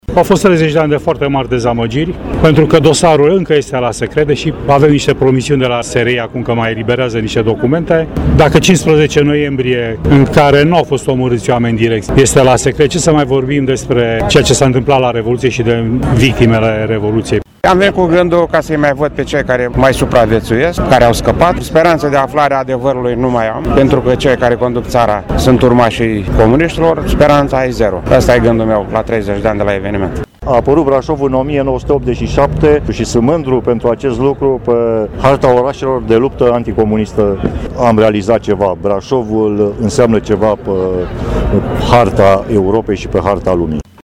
Ceremonial militar și religios, la 30 de ani de la revolta anticomunistă de la Brașov